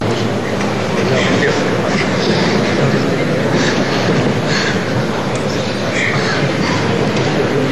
Crowd atmospheres 2
Free MP3 crowd atmospheres 2